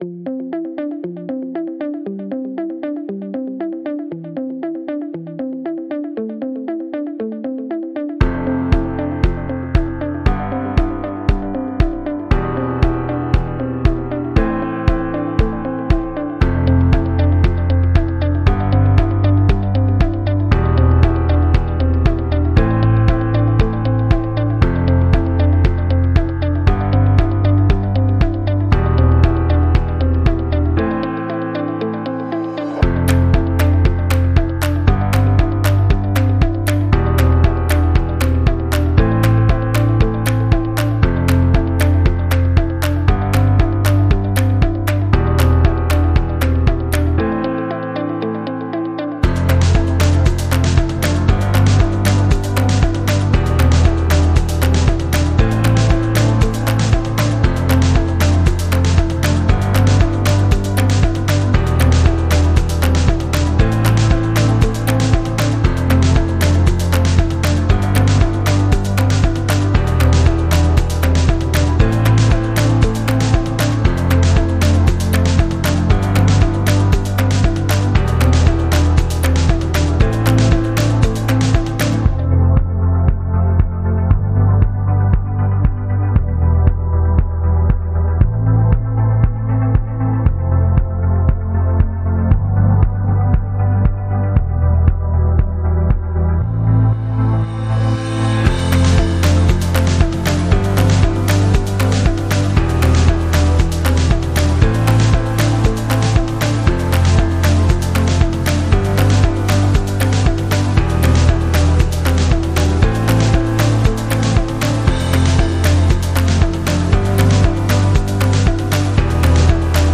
Uplifting and motivating track